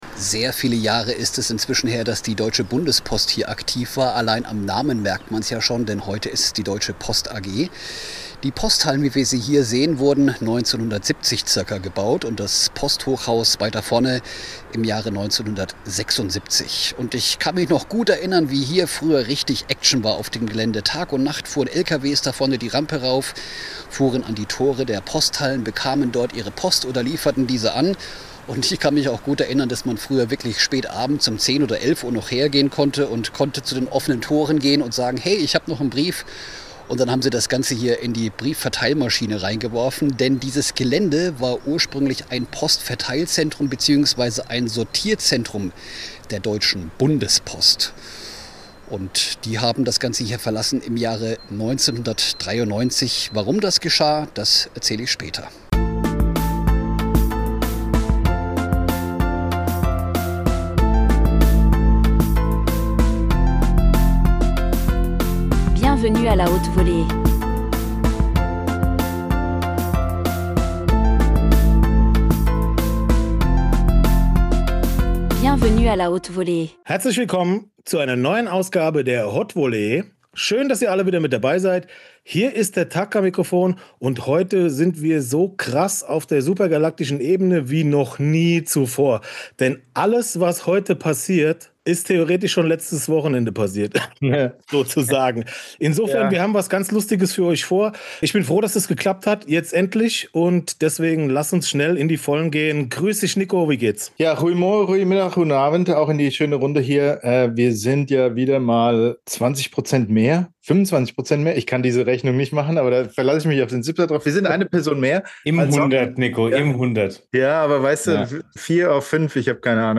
In dieser Folge erzählt er, wie alles begann, welche Bands legendär waren, wer den Backstagebereich zum Trümmerfeld gemacht hat und welche absurden Star-Allüren ihn bis heute zum Kopfschütteln bringen. Ein großartiges Interview mit einem tollen Typen, der mehr Musikgeschichte erlebt hat als manch einer hören kann.